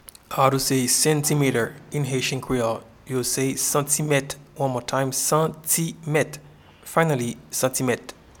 Pronunciation and Transcript:
Centimeter-in-Haitian-Creole-Santimet.mp3